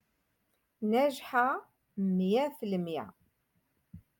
Moroccan Dialect-Rotation Five- Lesson Forty Nine